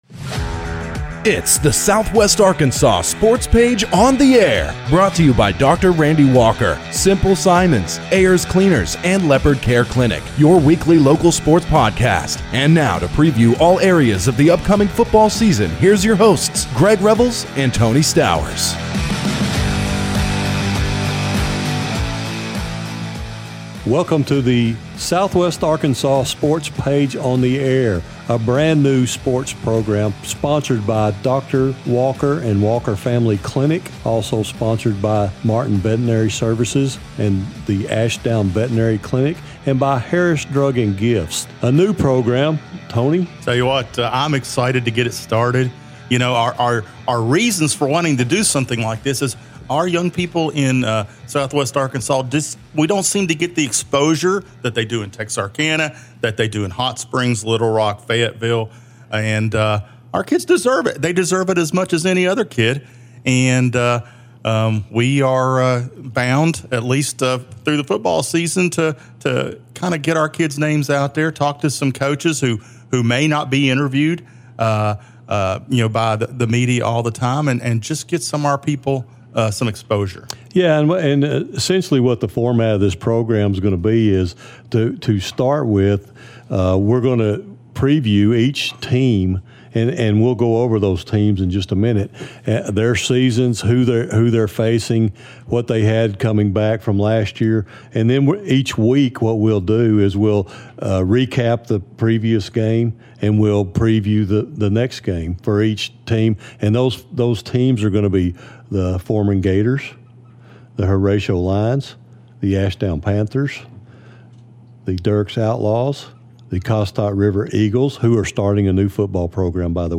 -Leopards talk and interview